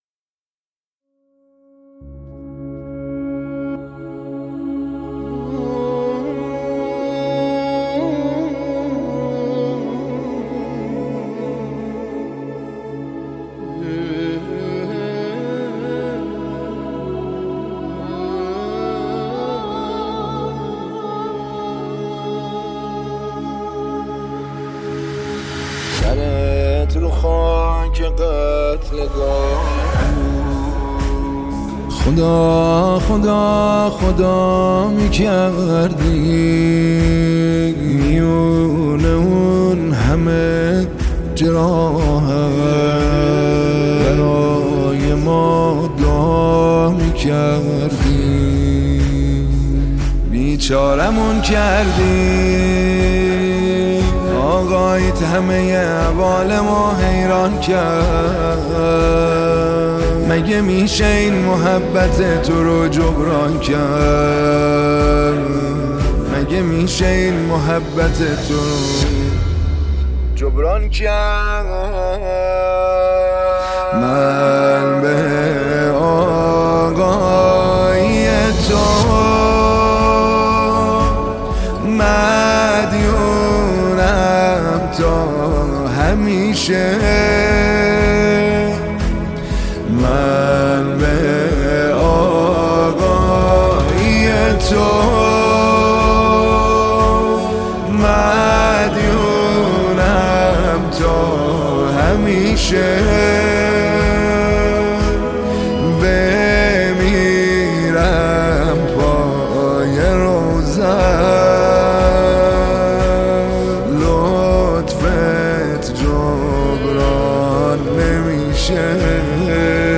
نماهنگ دلنشین
مداحی حماسی